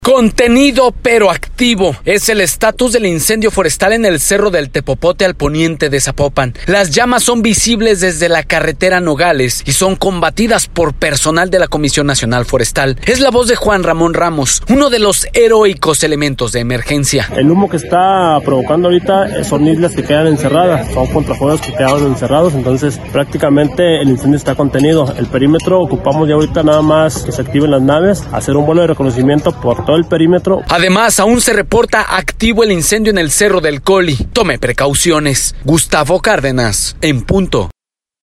uno de los heroicos elementos de emergencia.